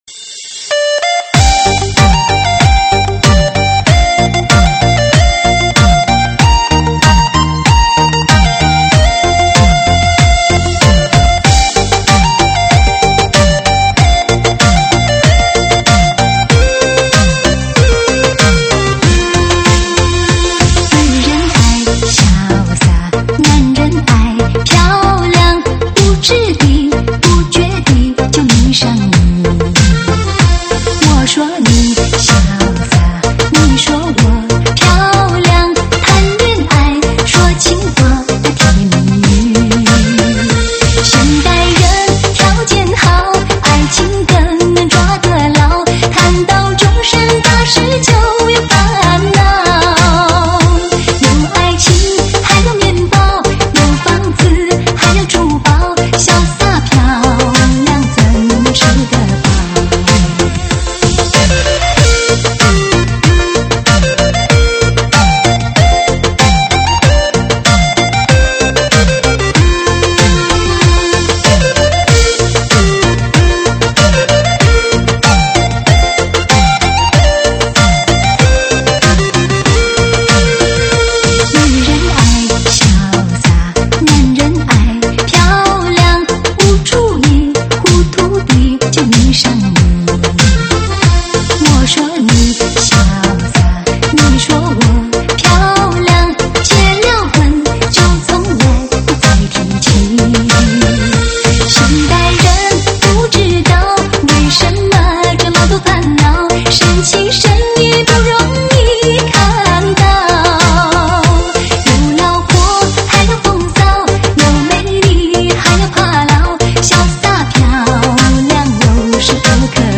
舞曲类别：独家发布